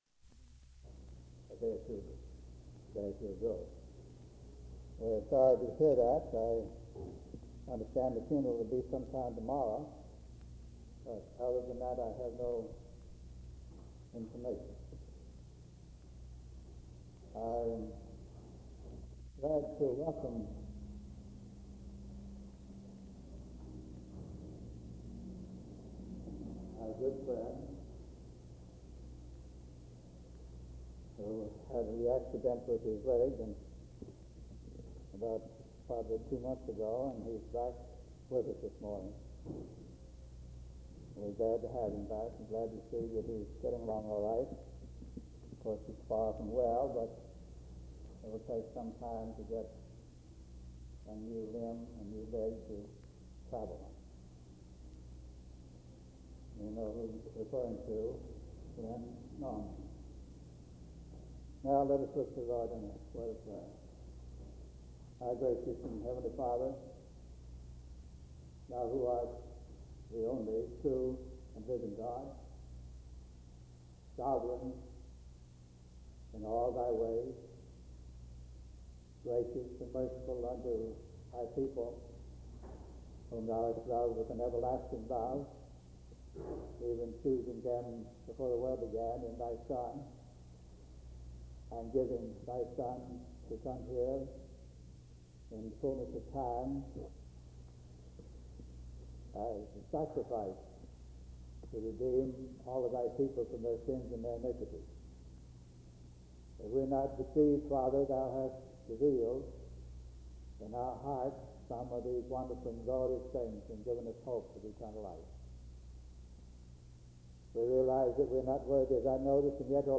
• Partial recording of an introduction and a prayer at an unknown location and time